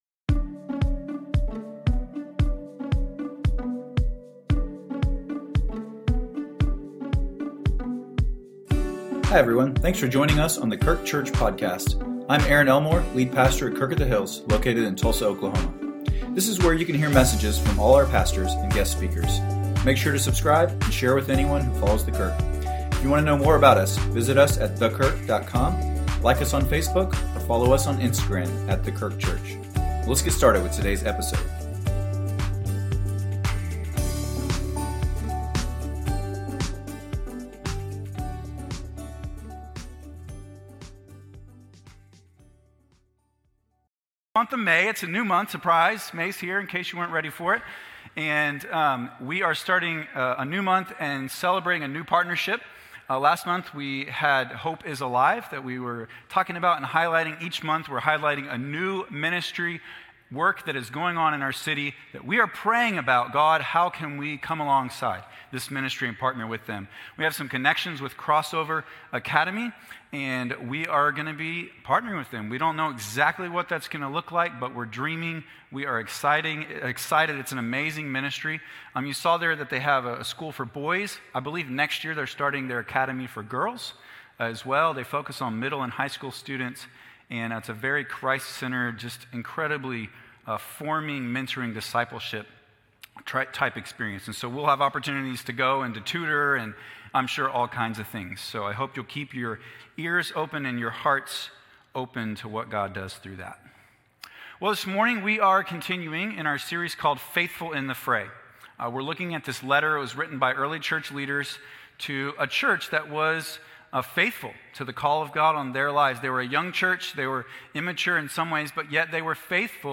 A message from the series "Faithful in the Fray."